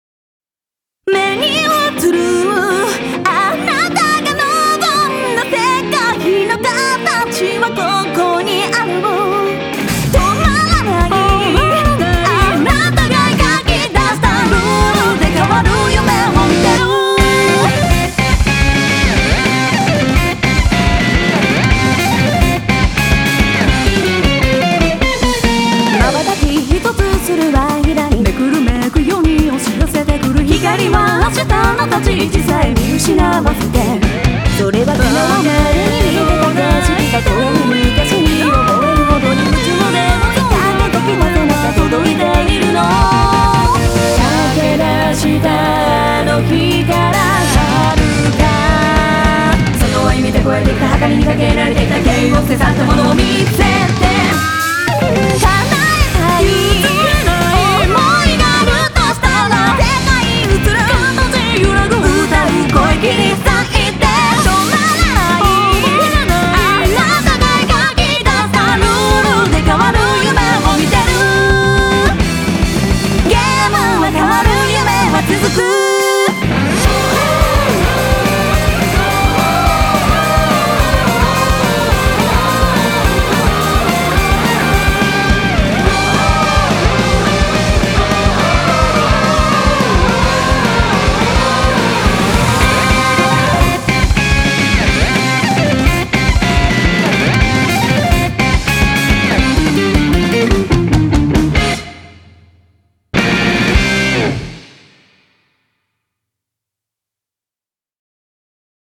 BPM142-213
Audio QualityPerfect (High Quality)
Soflan! Everyone's favourite!